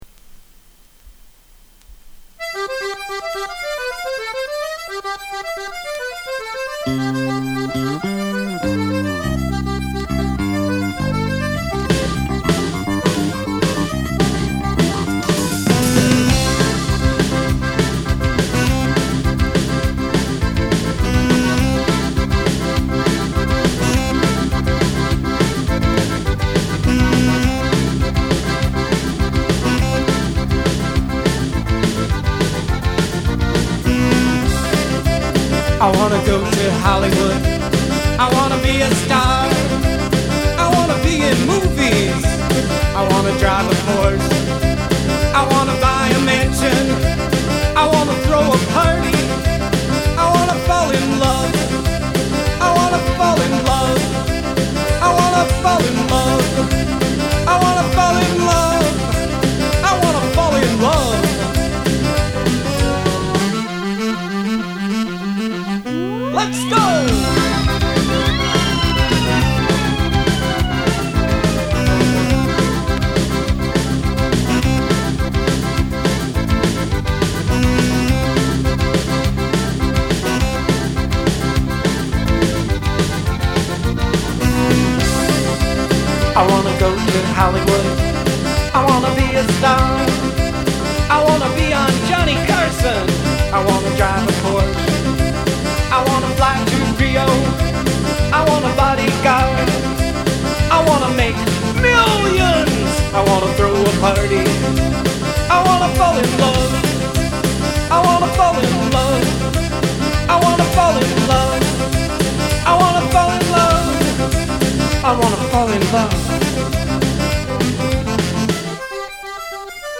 Accordion!!!
Catchy. Bouncy.